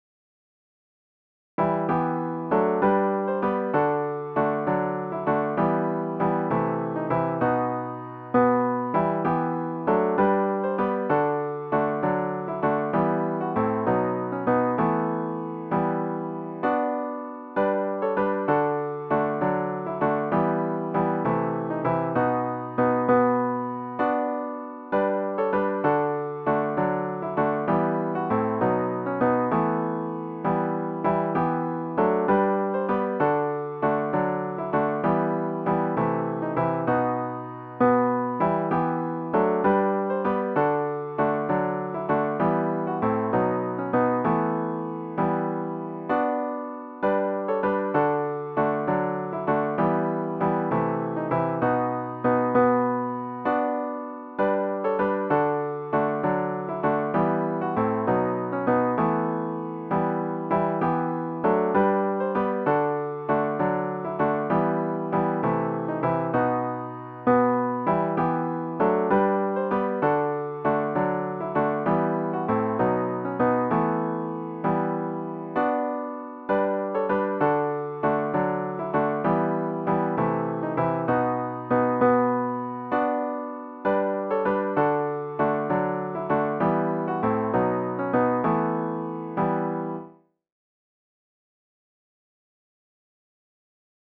OPENING HYMN   “What Child Is This”   GtG 145
ZZ-145-What-Child-Is-This-3vs-piano-only.mp3